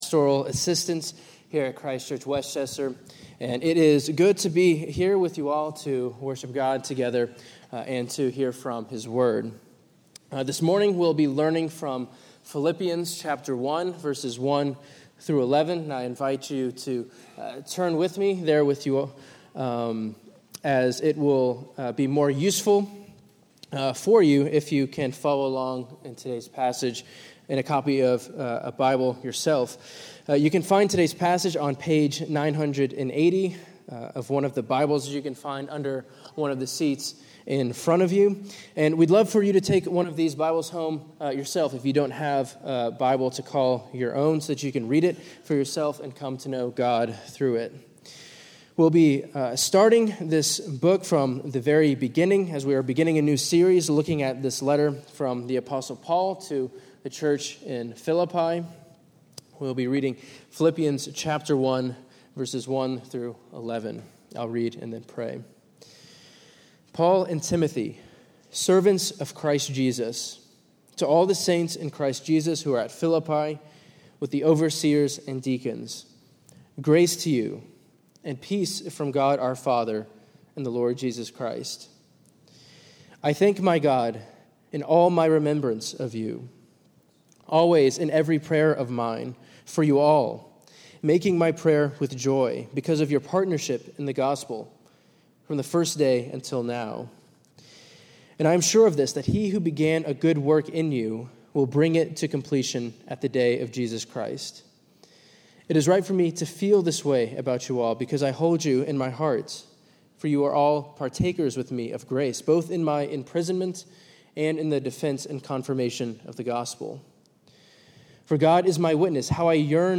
Sermon-929-1.mp3